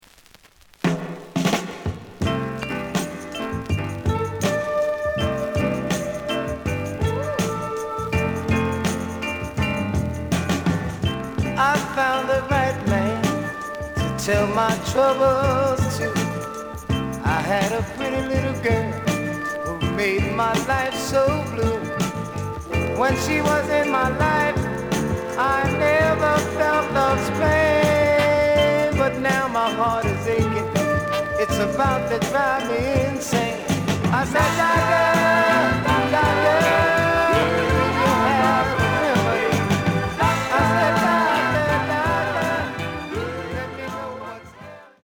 The audio sample is recorded from the actual item.
●Genre: Soul, 70's Soul
Looks good, but slight noise on both sides.)